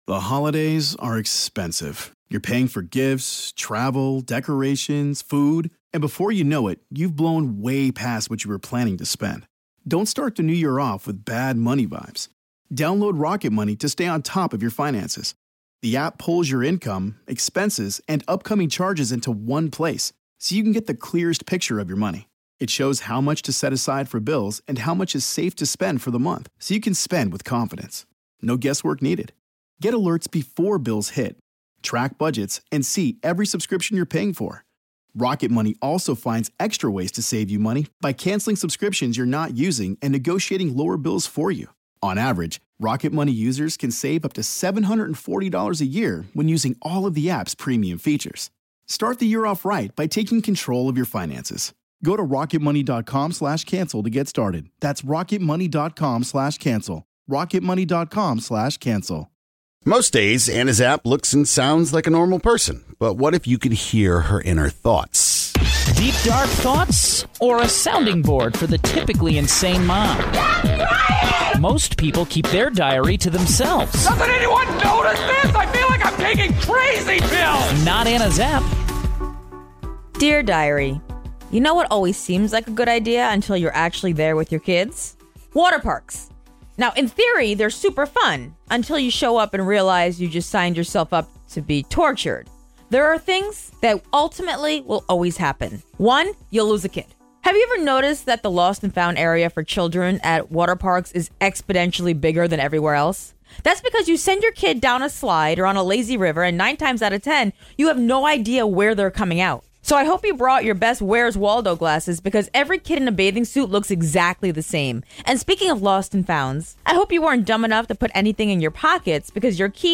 reading a page from her diary. Today, she reveals that the happiest place to be this summer may actually be hell in disguise.